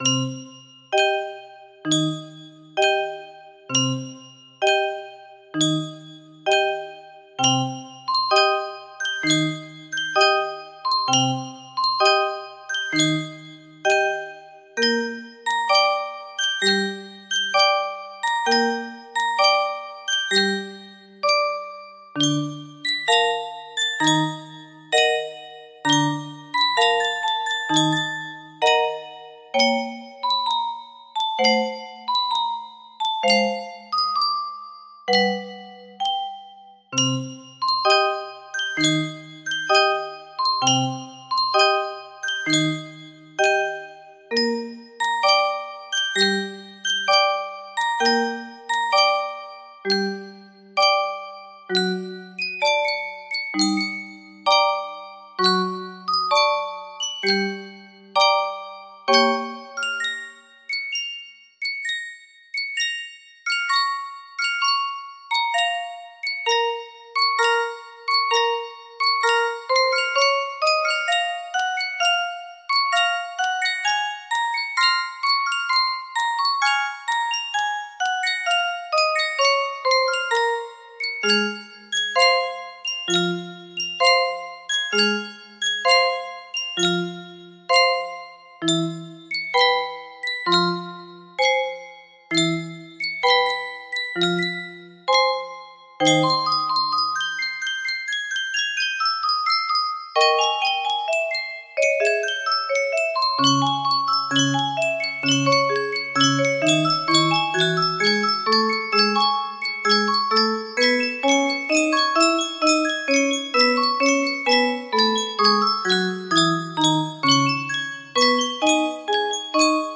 Full range 60
Classical piece by genre.
I opted for a slower 65 bpm tempo.